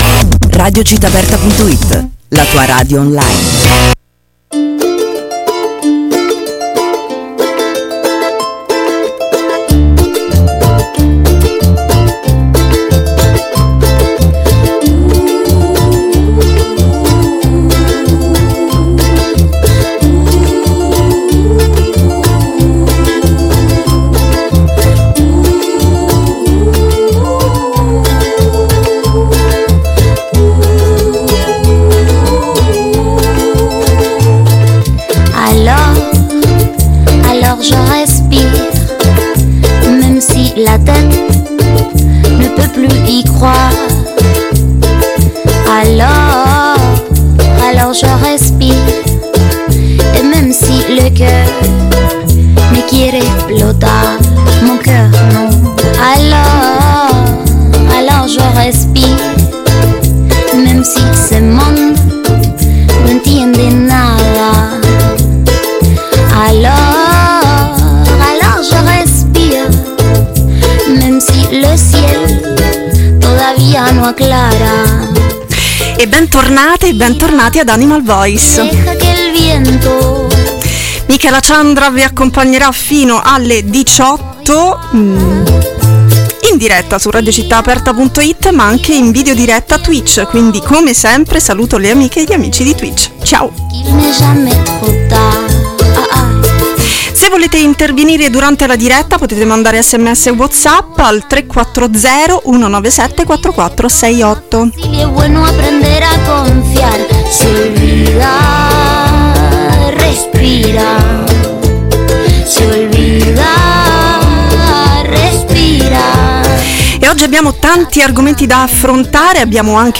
SCALETTA MUSICALE